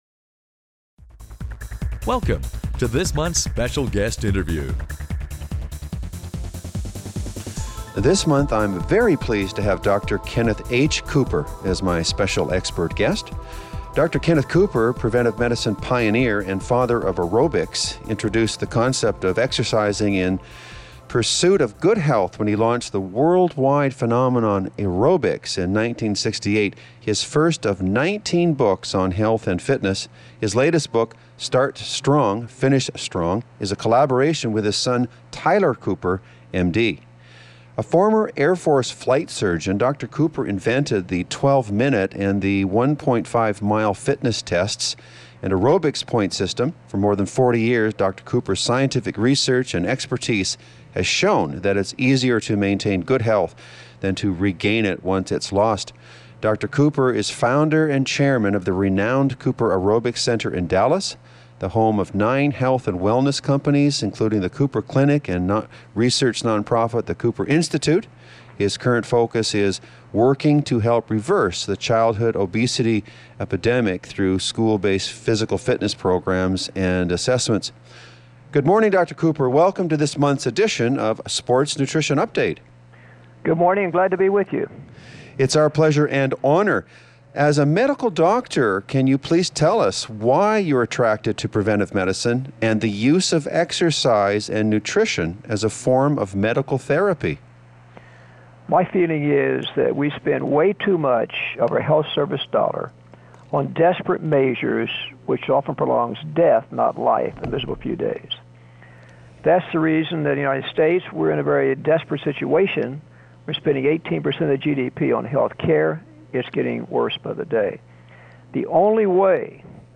Special Guest Interview